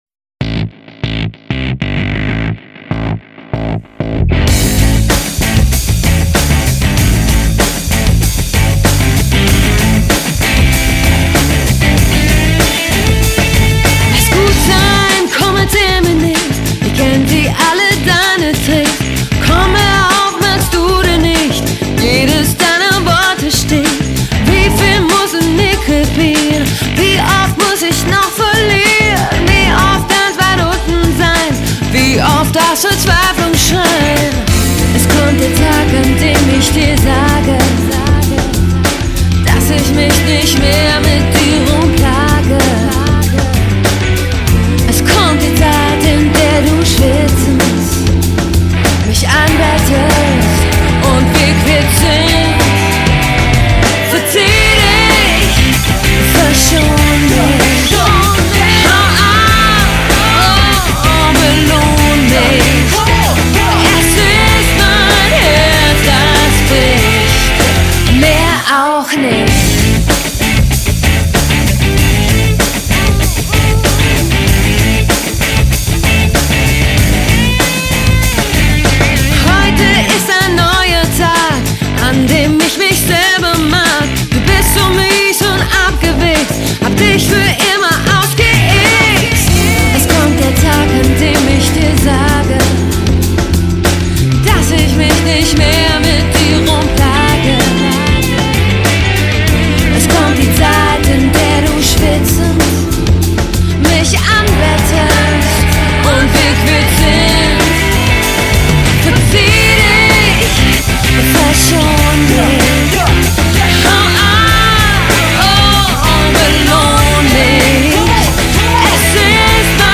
Genre: Kampagnen-Musik